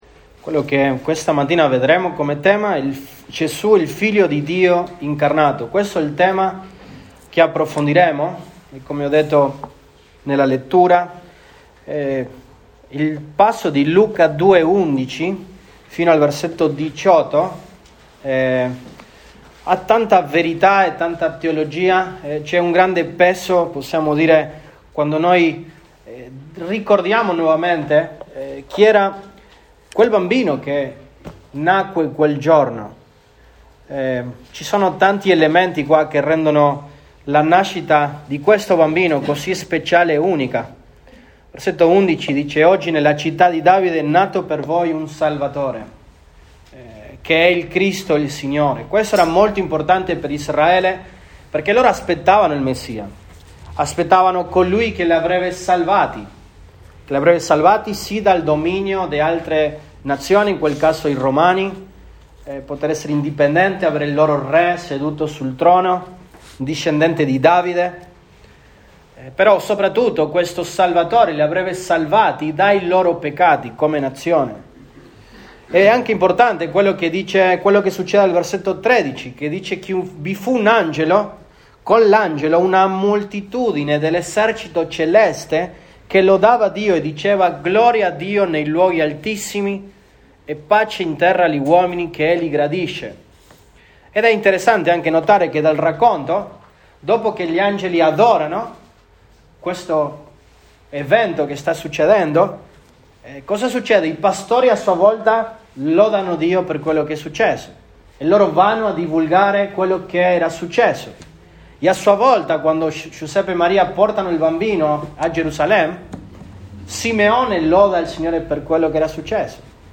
Dic 26, 2021 Gesù Cristo, il Figlio di Dio incarnato MP3 Note Sermoni in questa serie Gesù Cristo, il Figlio di Dio incarnato.